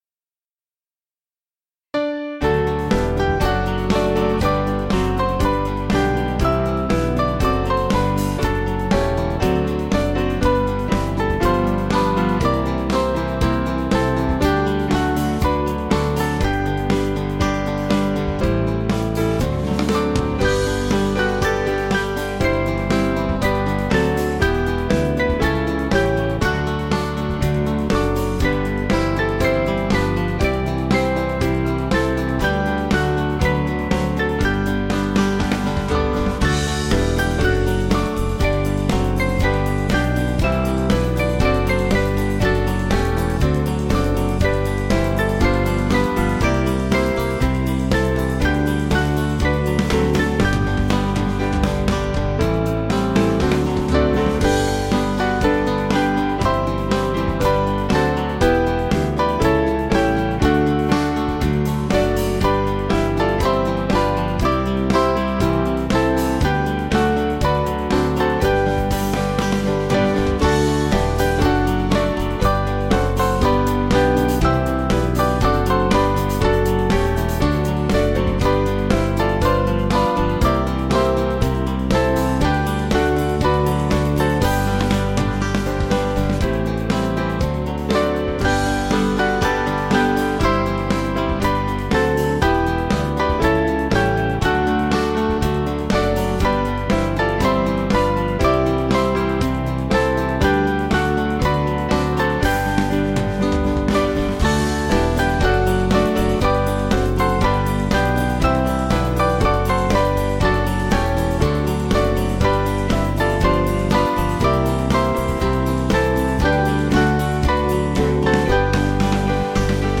7.6.7.6.D
Small Band